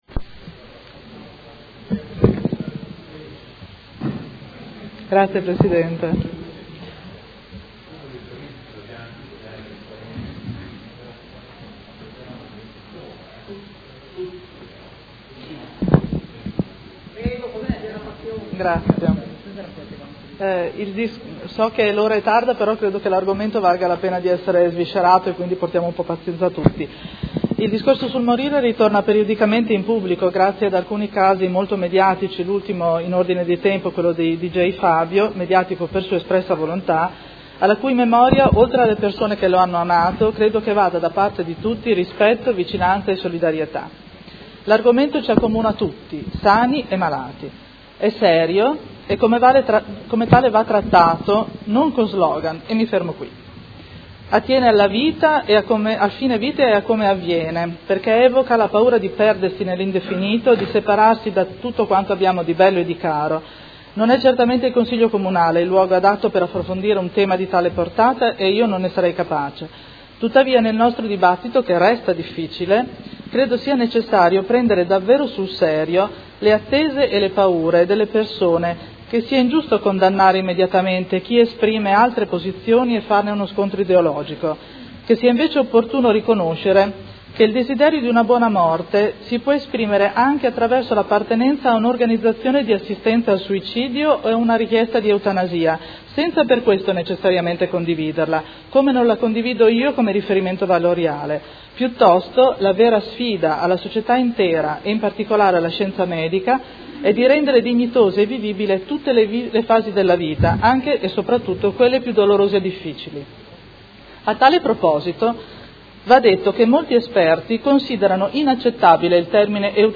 Chiara Pacchioni — Sito Audio Consiglio Comunale